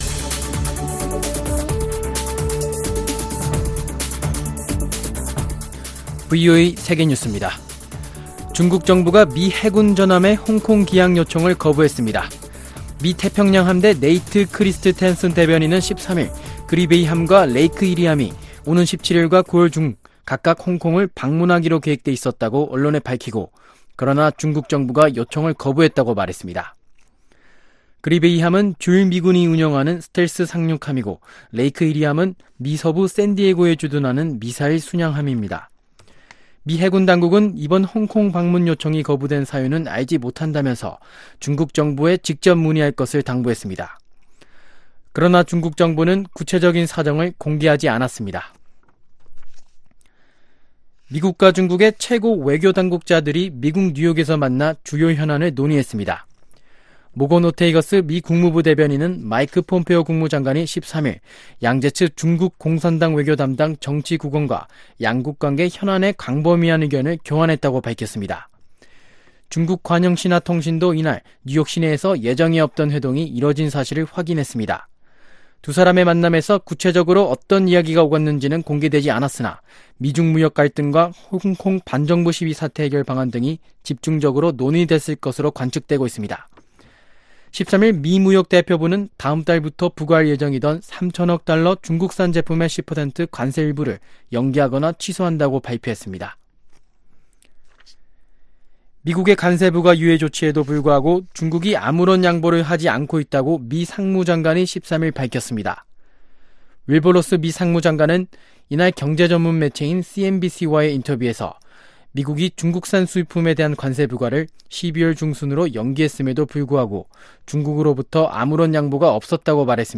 VOA 한국어 아침 뉴스 프로그램 '워싱턴 뉴스 광장' 2019년 8월 15일 방송입니다. 미국 정부 고위 당국자는 최근 북한이 발사한 신형 미사일에 대한 러시아의 기술 지원설에 대해 기밀 사안이라고만 언급했습니다. 북한 내 결핵치료약이 내년 6월이면 바닥날 것으로 우려된다고 유엔 산하 결핵퇴치 국제협력사업단이 밝혔습니다.